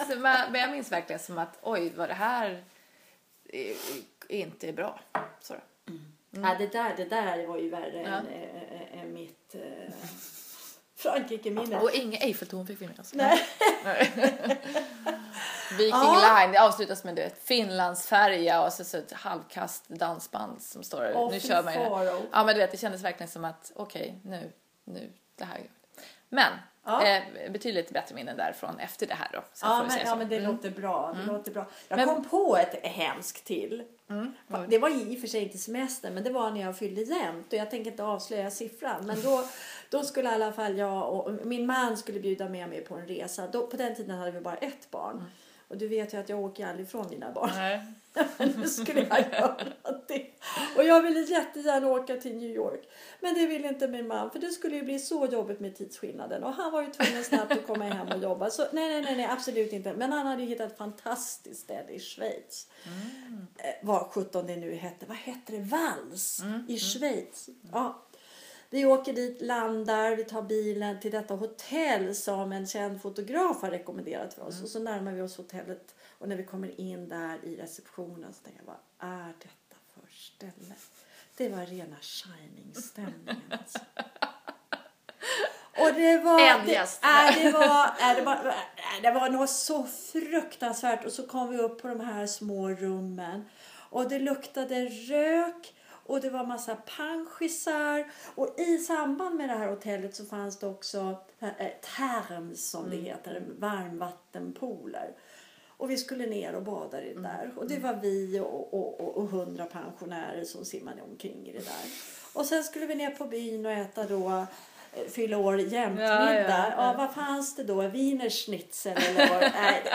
Renews redaktörer pratar ledighet inför stundande semester.